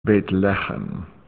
bayth leh'-khem — "bayth" is literally "house", and "leh'-khem" is literally "bread".
Click here to hear the Hebrew pronunciation of "Bethlehem".
bethlehem_hebrew.mp3